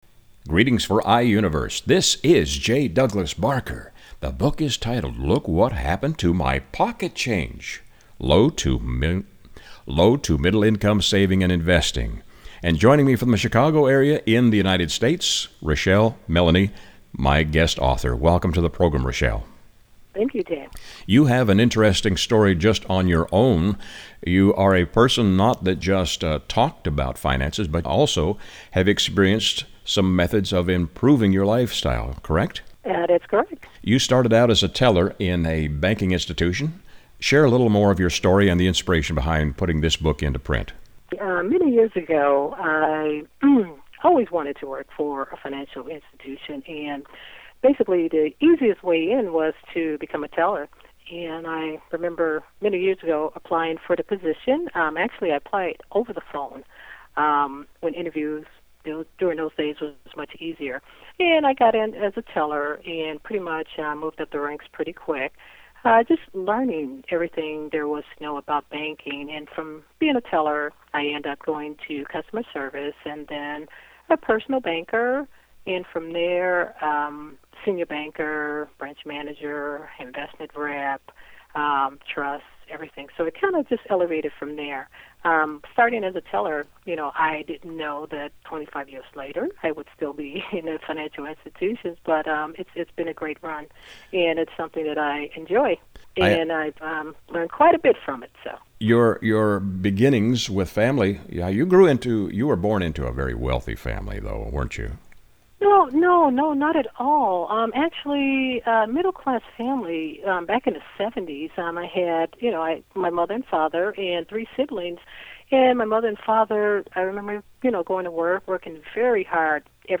Author Radio Interview